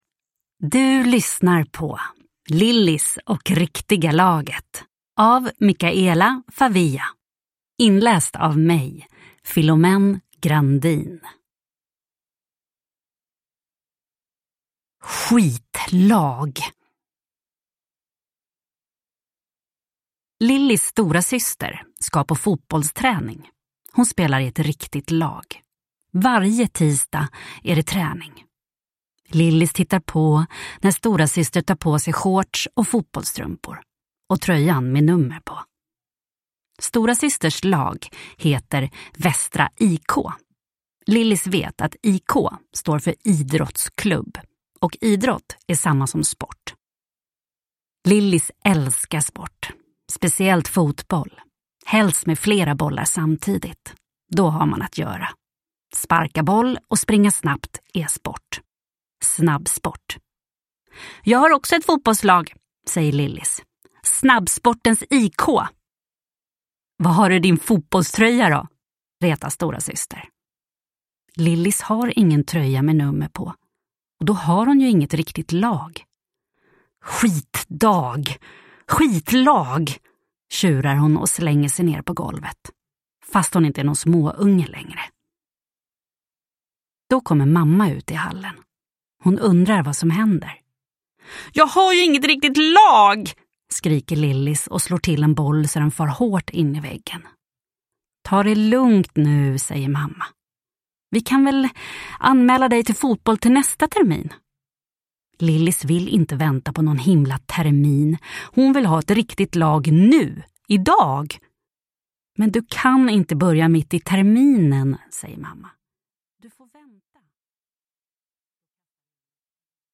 Lillis och riktiga laget – Ljudbok – Laddas ner